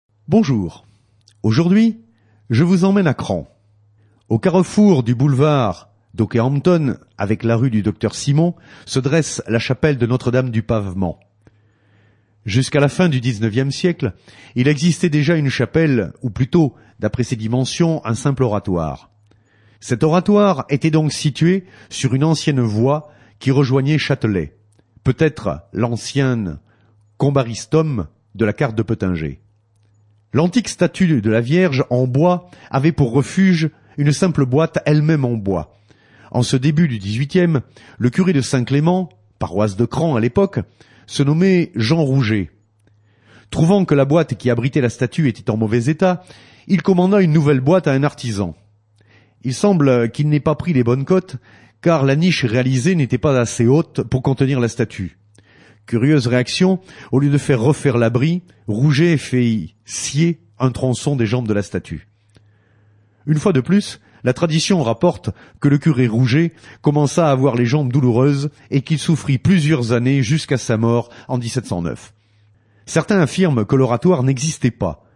La S.AH.M. sur Radio Fidélité